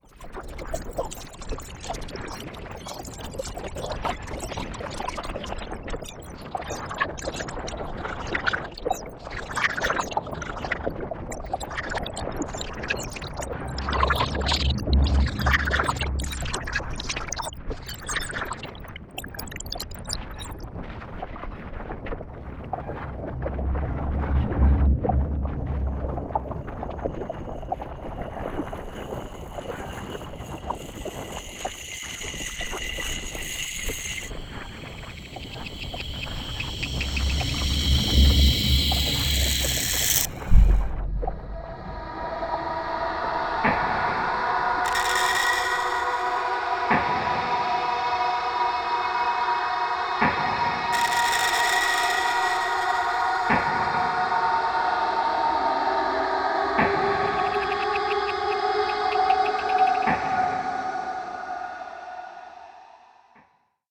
Acousmatic
• SHINRA (8 channel, 8’05“, Cologne, 2011)
There was the recording of cutting-tree. It includes various kinds of noises and sounds. Percussive attack of cutting, noises of rubbing leaves and tearing branches and more, then finally a noise of bumping to the ground of falling.